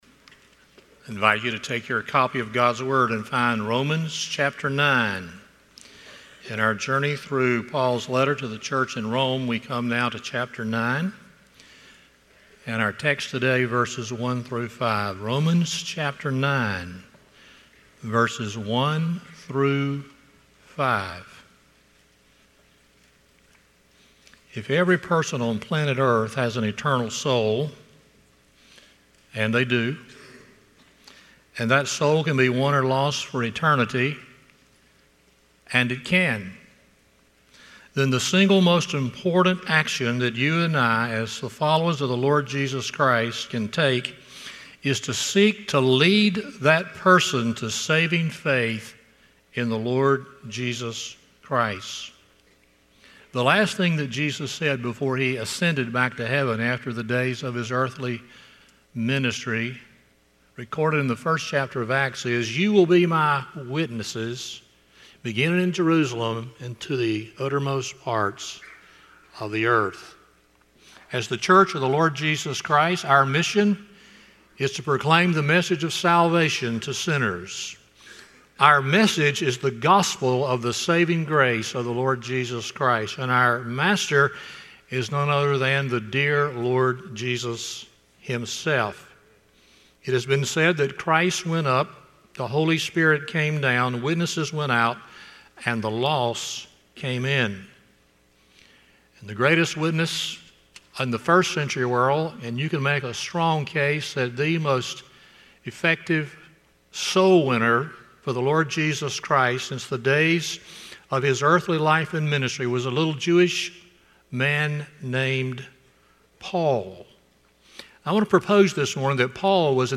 Romans 9:1-5 Service Type: Sunday Morning 1.